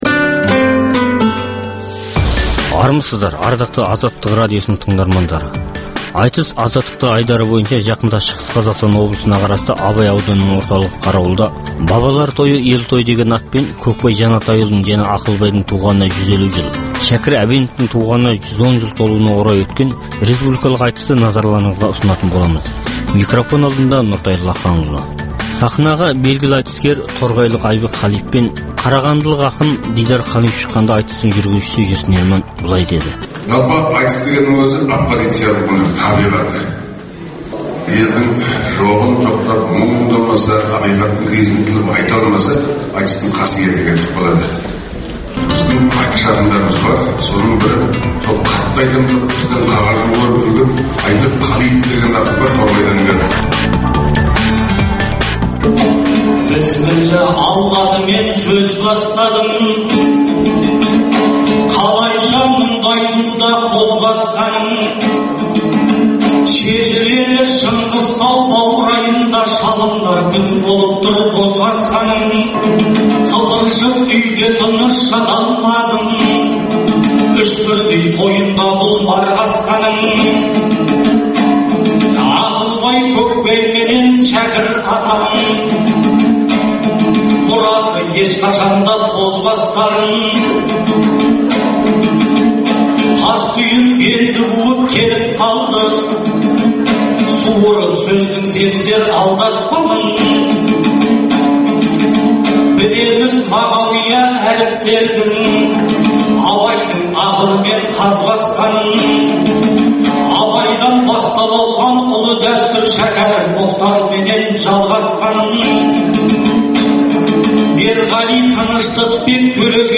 Айтыс Азаттықта